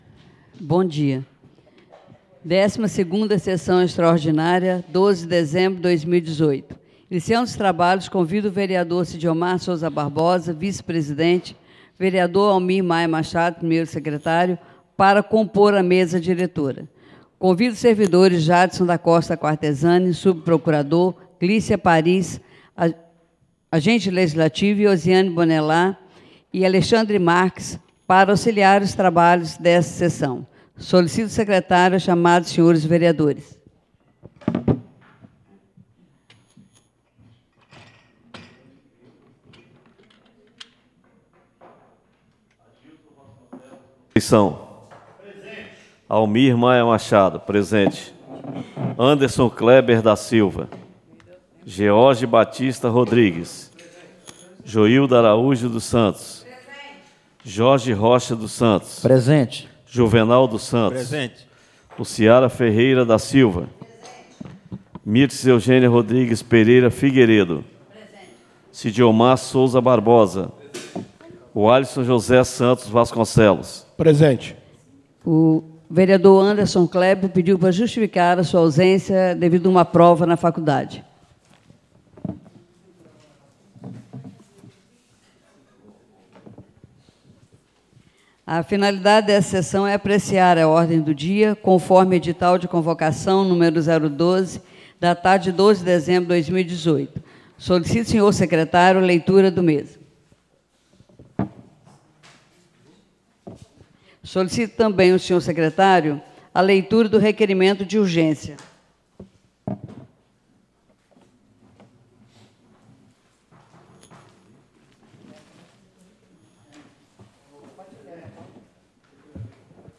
12ª SESSÃO EXTRA DO DIA 12 DE DEZEMBRO DE 2018